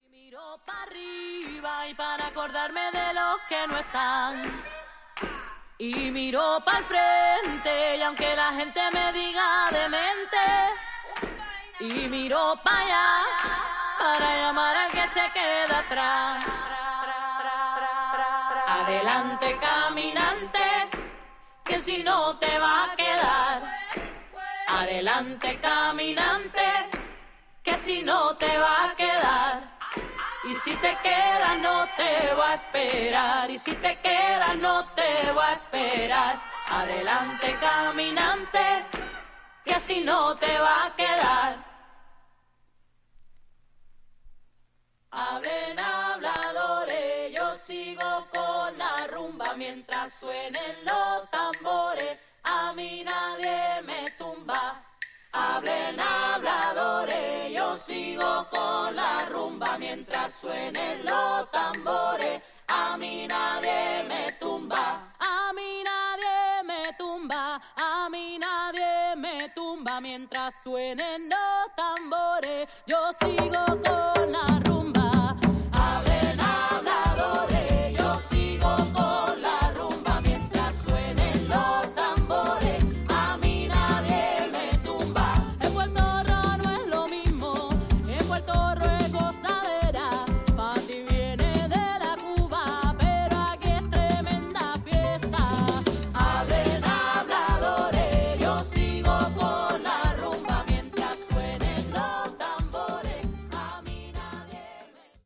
Tags: Tropical , Spain